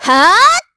Ripine-Vox_Attack5_kr.wav